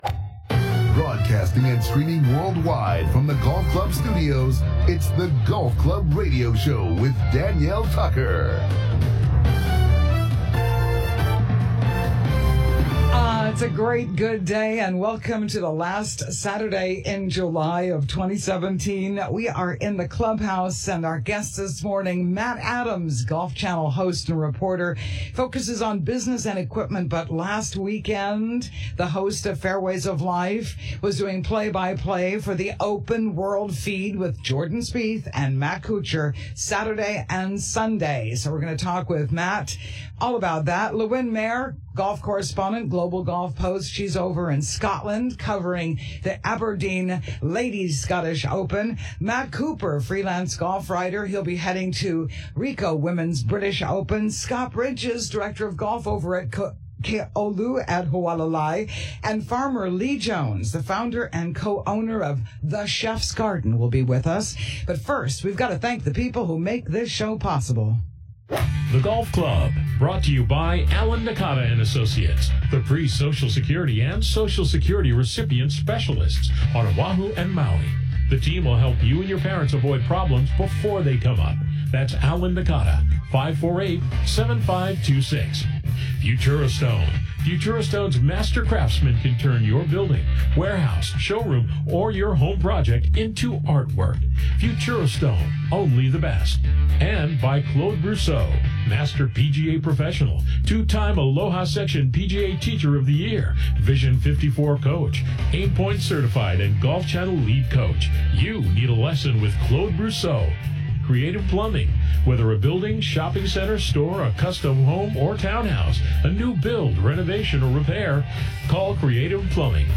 broadcasting state-wide talking to Hawaii's Golf Pros and across America sports shrinks, authors, mental coaches and PGA broadcasters.